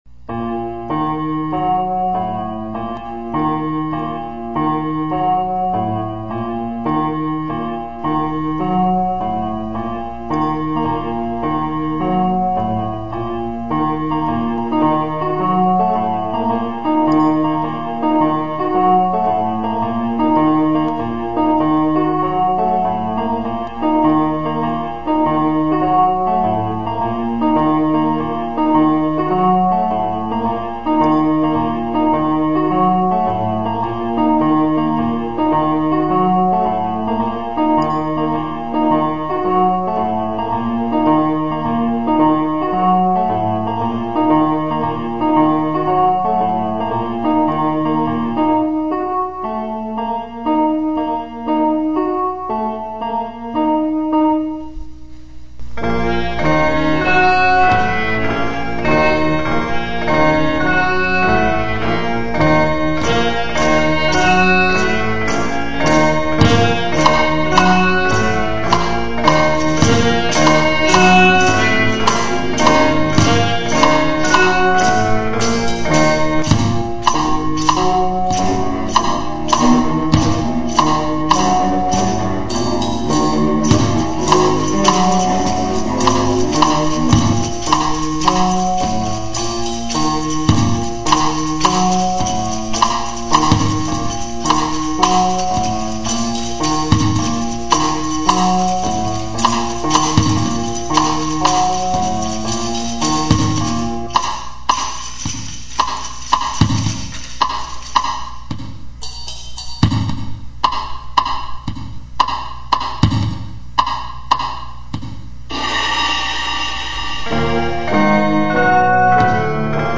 Instruments: Keyboard, clavietta, tambourine, cello.